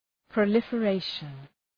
{prə,lıfə’reıʃən}
proliferation.mp3